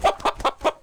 CosmicRageSounds / wav / general / combat / creatures / CHİCKEN / he / attack1.wav
attack1.wav